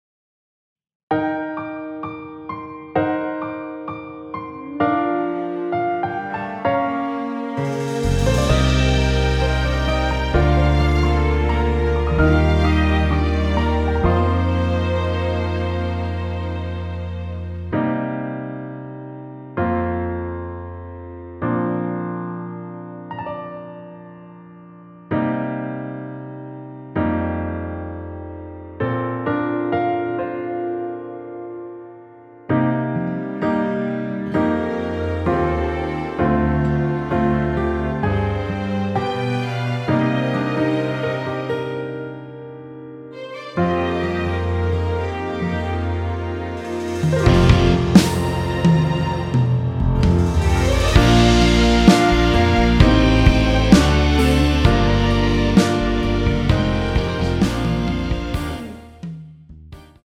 앞부분30초, 뒷부분30초씩 편집해서 올려 드리고 있습니다.
중간에 음이 끈어지고 다시 나오는 이유는